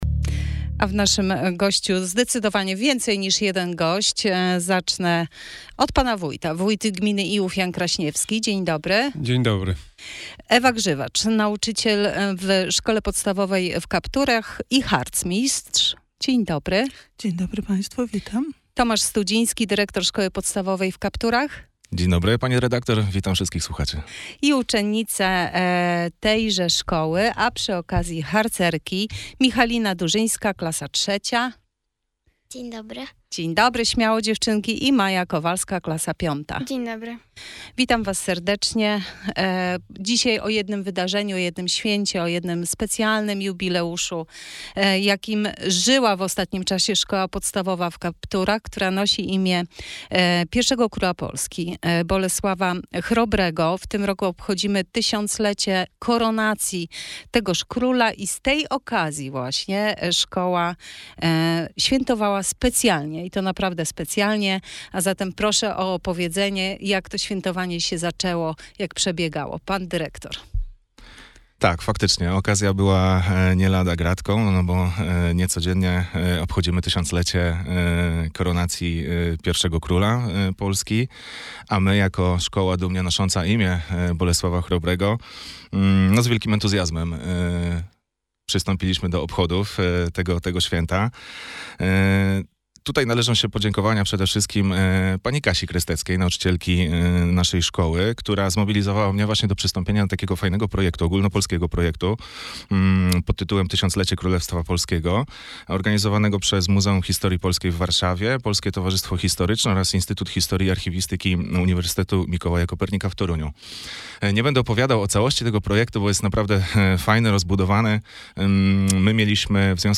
Wywiad w Radio Sochaczew - Najnowsze - Gmina Iłów